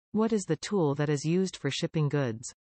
You will hear a question.